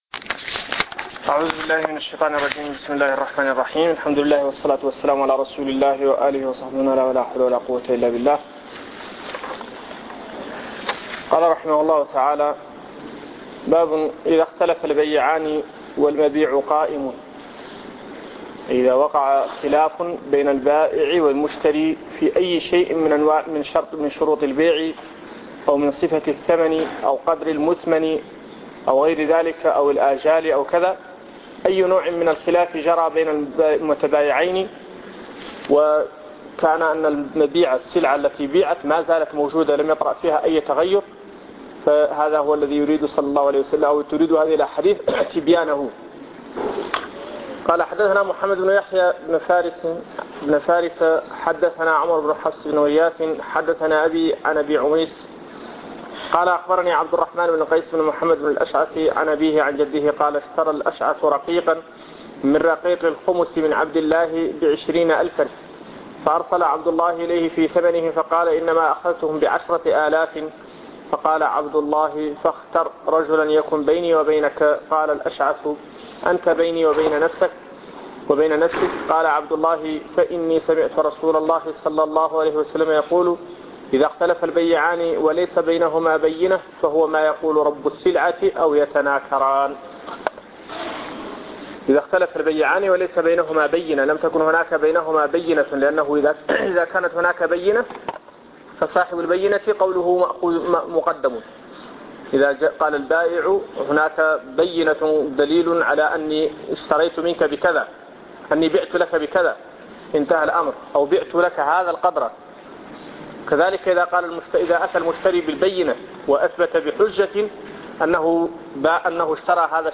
شرح كتاب البيوع من سنن أبي داود الدرس 15